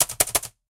Re-write ASCII Typewriter sound effect OGG file
ascii-typewriter.ogg